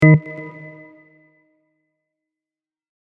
Buttons and Beeps
Blip 3.mp3